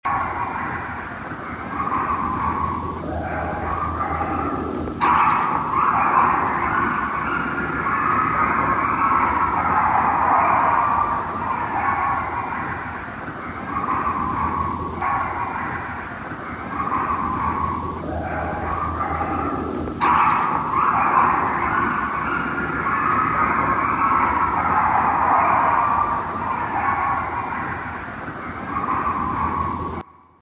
Coyote Chorale
The result is a chorus of warbling yips, howls, barks, and more.
However, Seward Park is an urban park and the Forest’s coyotes also respond to urban sounds.
coyoteChorale.mp3